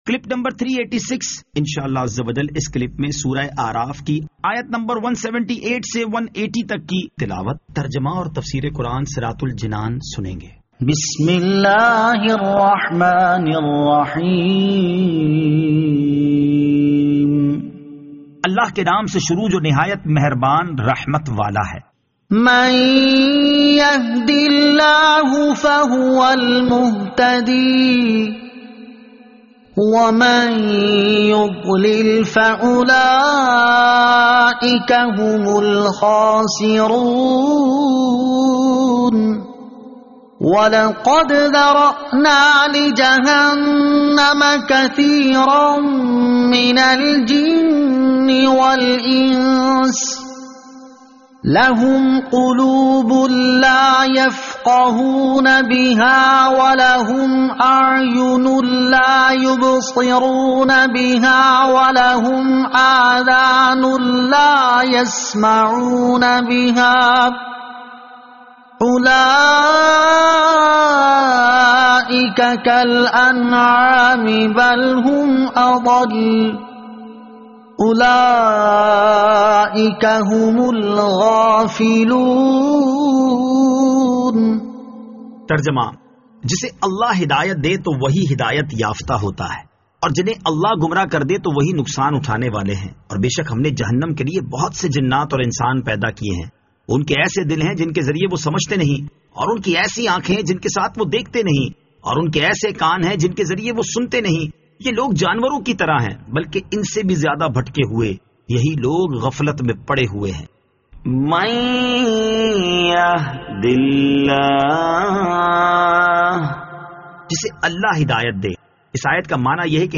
Surah Al-A'raf Ayat 178 To 180 Tilawat , Tarjama , Tafseer